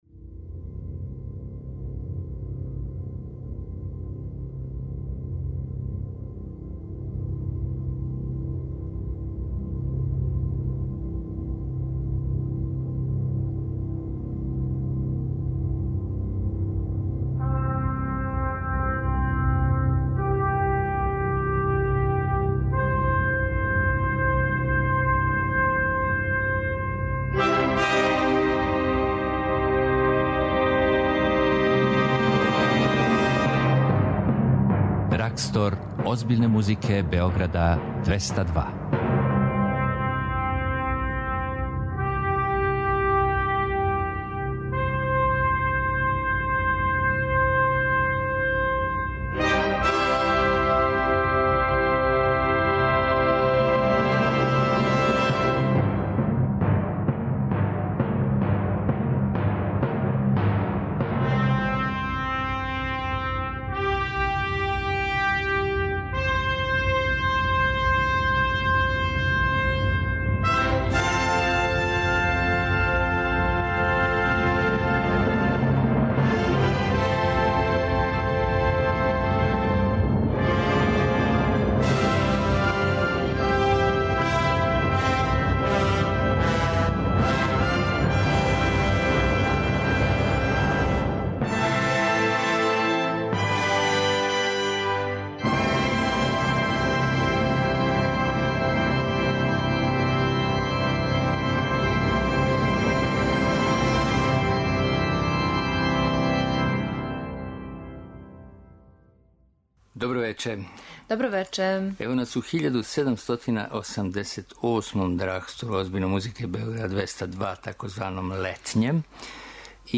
Култна емисија Београда 202 која промовише класичну музику.